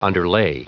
Prononciation du mot underlay en anglais (fichier audio)